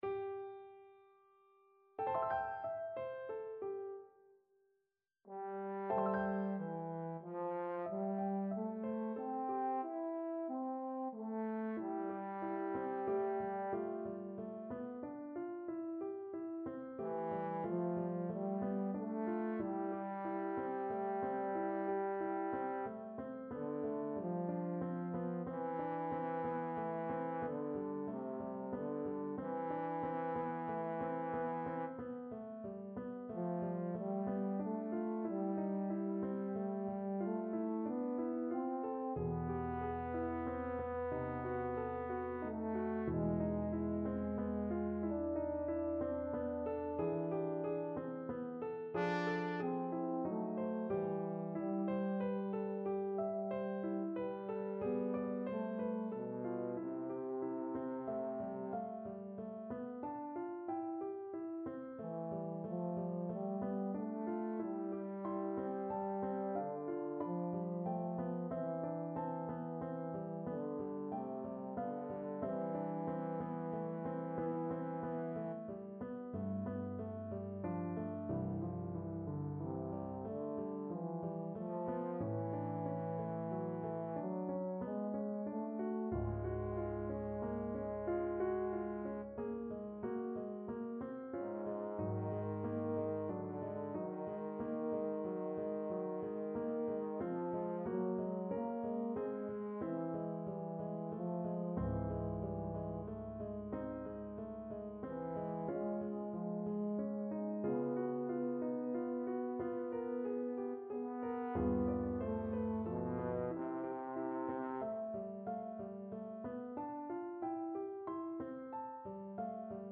Trombone version
Lento =92
6/4 (View more 6/4 Music)
Trombone  (View more Intermediate Trombone Music)
Classical (View more Classical Trombone Music)